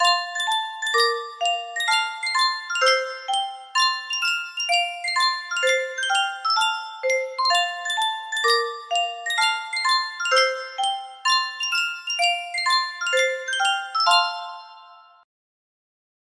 Sankyo Music Box - Bonnie Blue Flag EZC music box melody
Full range 60